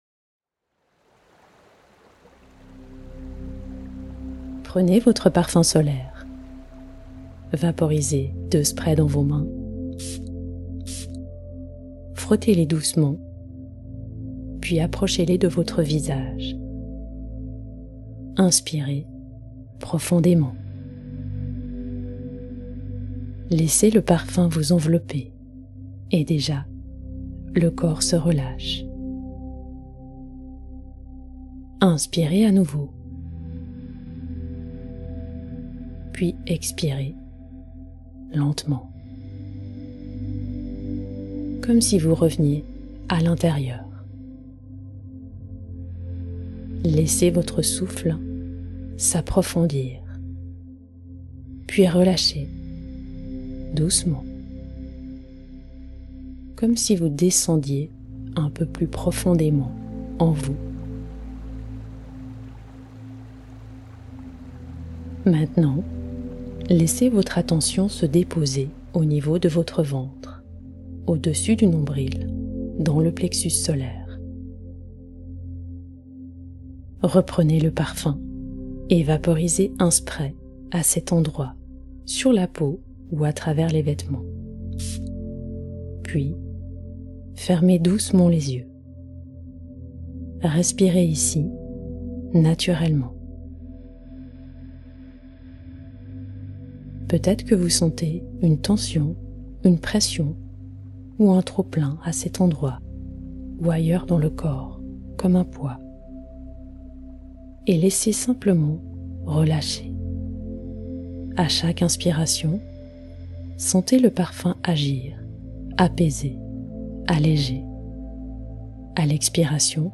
Rituel guidé - 3 minutes